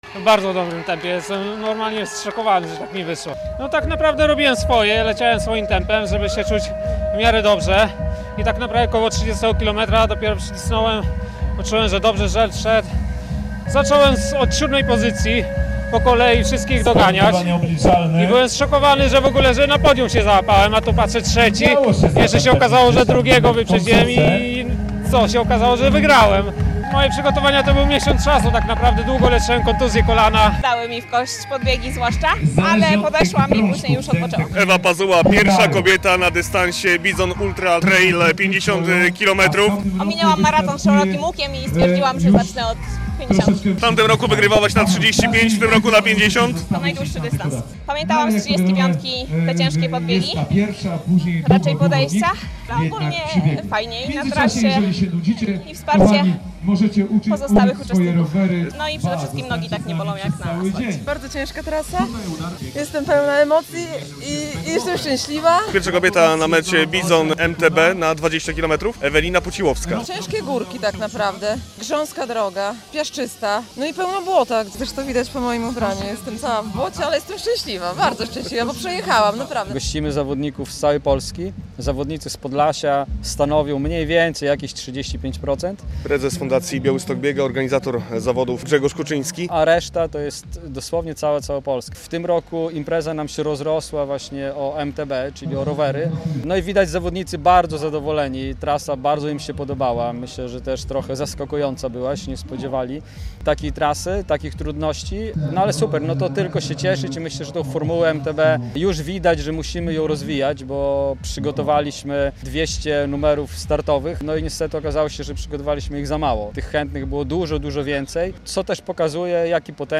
Za nami Bison Ultra Trail i Bison MTB przez Puszczę Knyszyńską - relacja